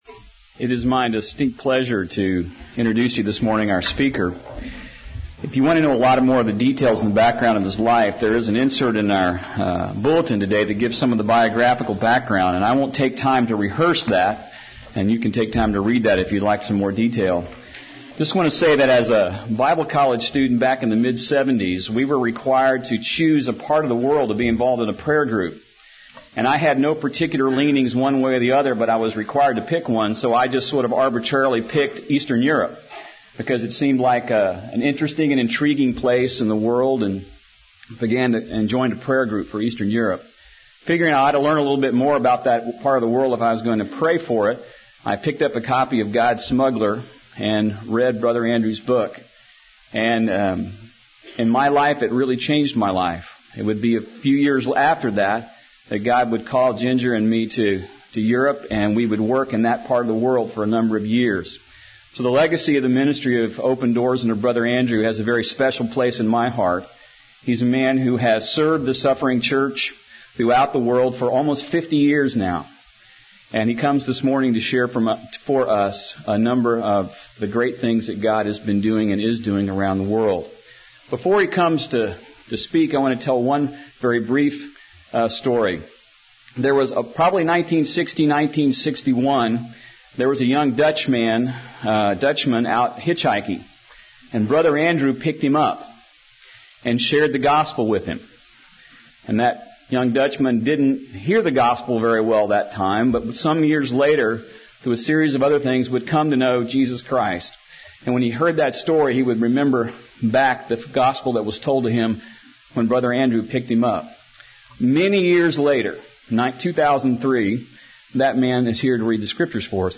A Special Message (1st Service) by Brother Andrew | SermonIndex